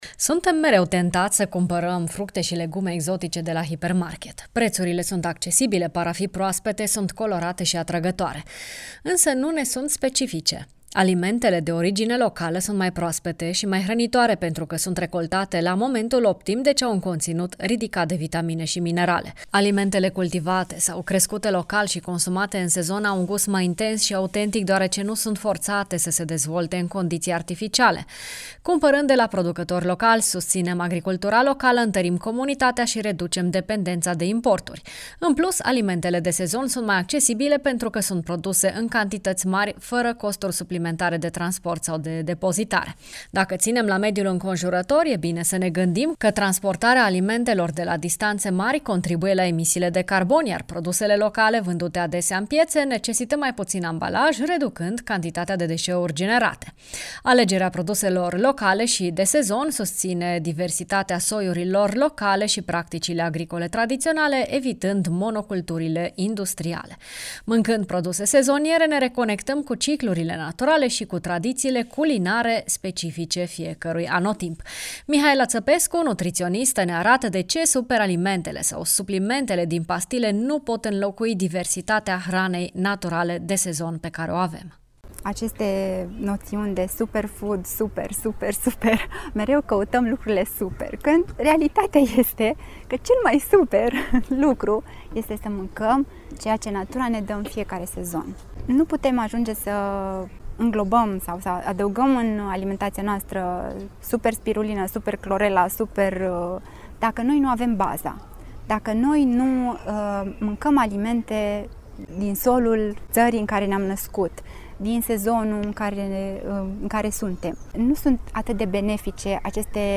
nutritionist